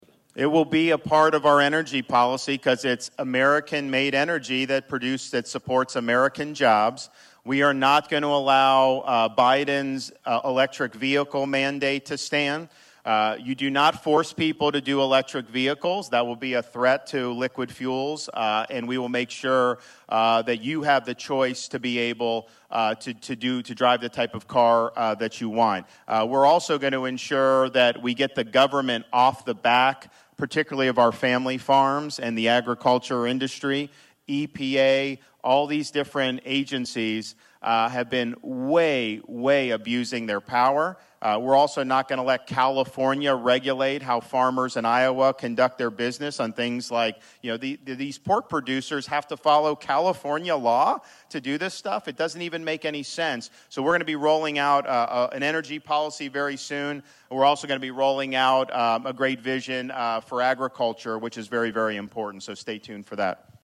(Atlantic, IA) – Florida Governor Ron DeSantis spoke to Atlantic citizens today on multiple issues, one being a focus on the economy.